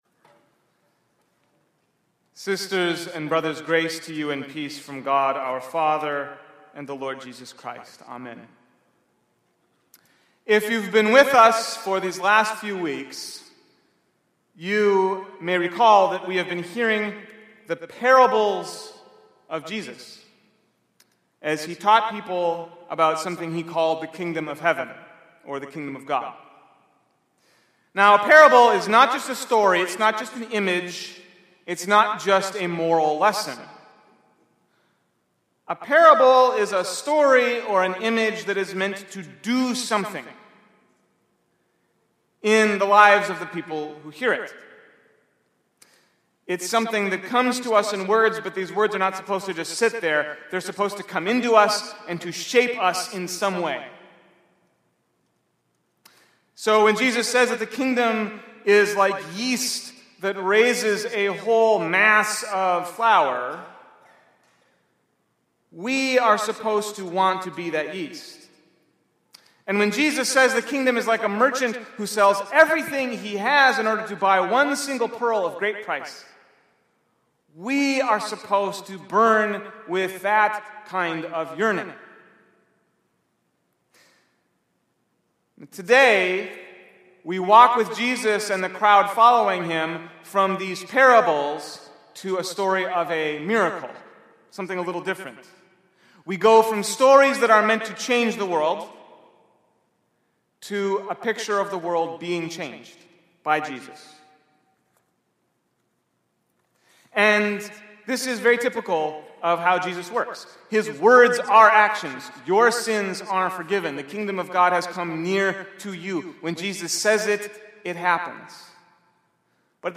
Sermon Rewind: No Strings Attached